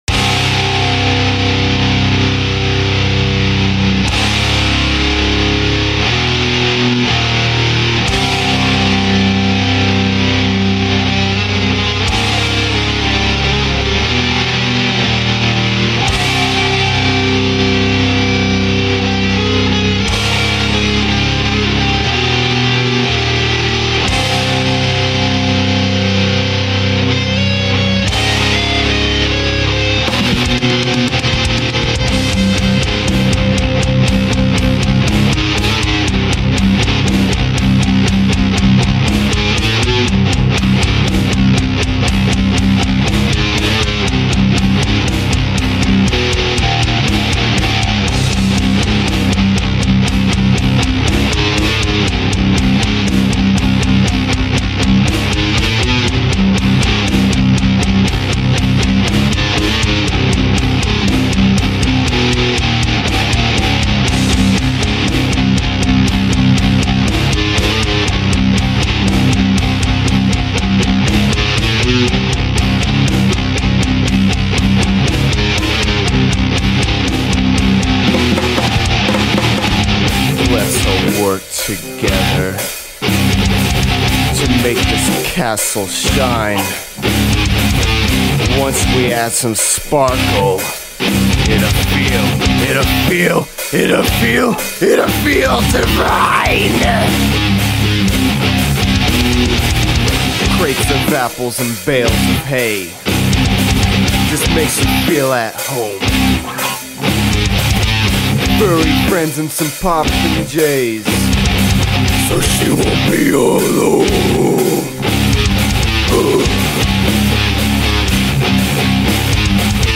My first real attempt at a deathcore/hardcore song.
genre:deathcore